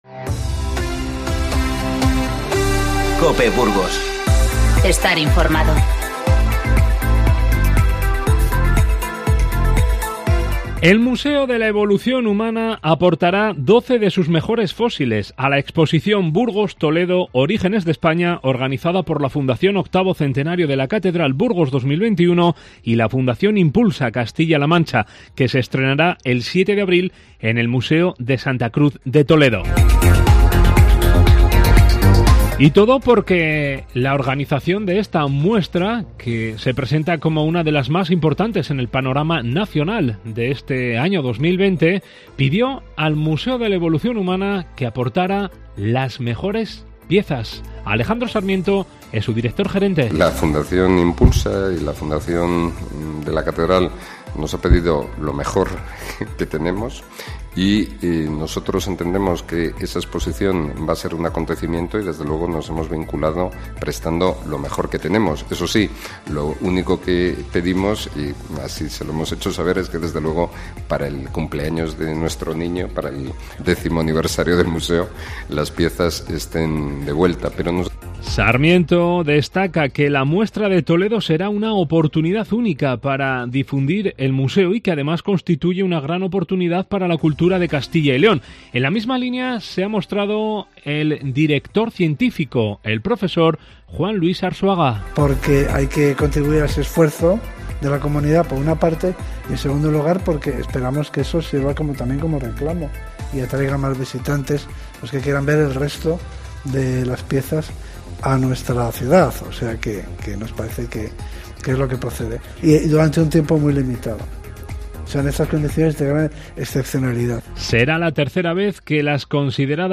Informativo 21-02-20